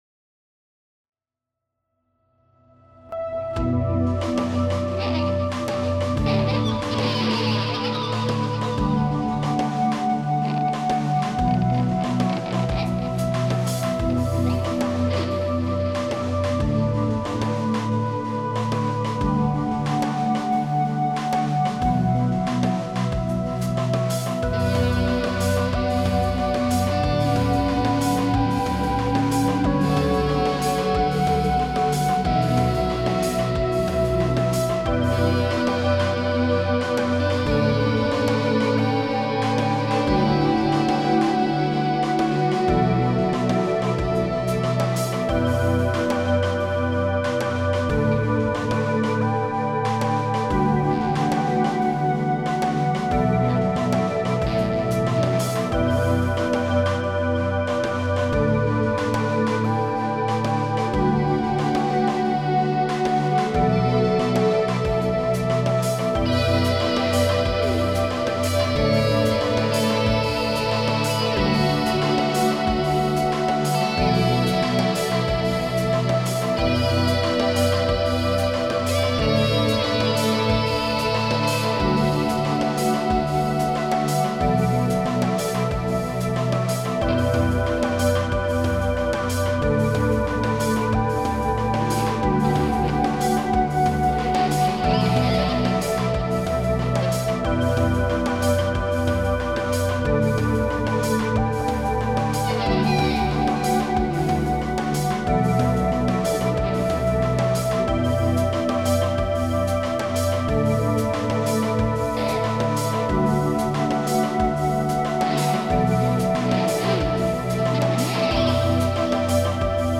Its pretty chill.
Sounds like some drum machines and keyboards...
guitar